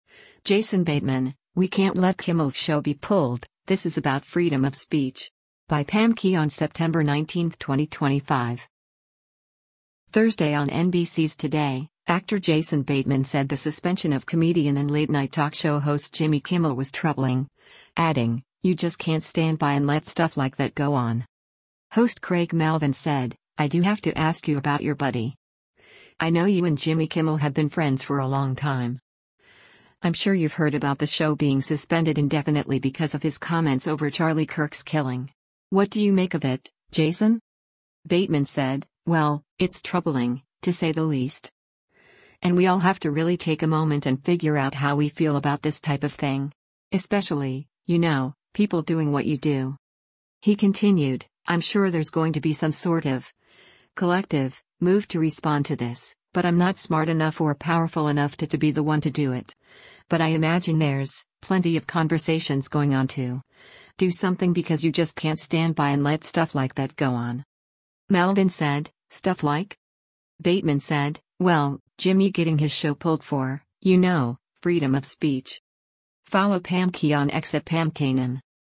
Thursday on NBC’s “Today,” actor Jason Bateman said the suspension of comedian and late-night talk show host Jimmy Kimmel was “troubling,” adding, “you just can’t stand by and let stuff like that go on.”